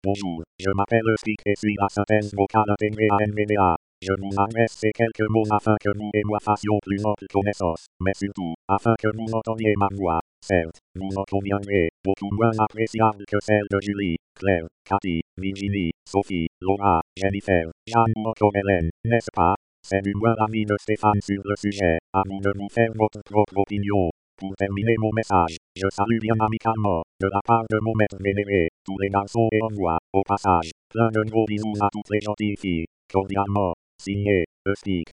Texte de démonstration lu par Espeak (Nouvelle mouture), synthèse vocale intégrée à la revue d'écran gratuite NVDA
Écouter la démonstration d'Espeak (Nouvelle mouture), synthèse vocale intégrée à la revue d'écran gratuite NVDA